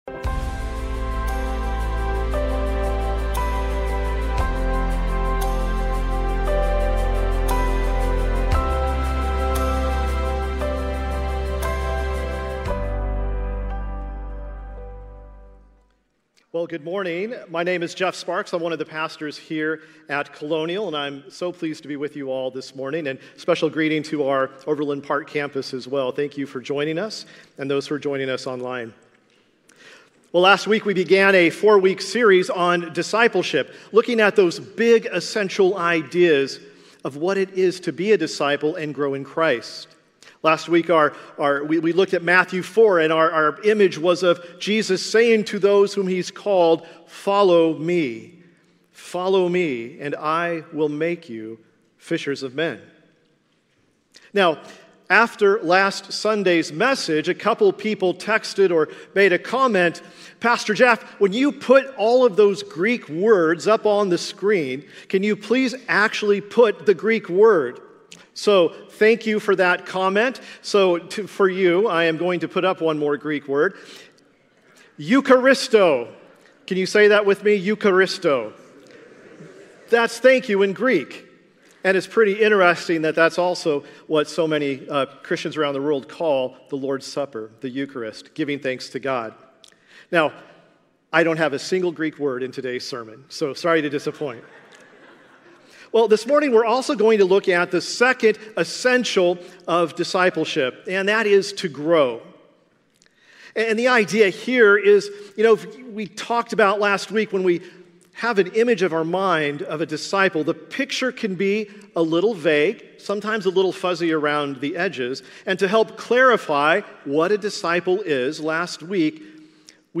2024 Discipleship Sunday Morning Pruning keeps the vine productive.